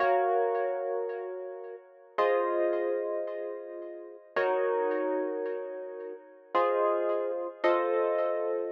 03 ElPiano PT4.wav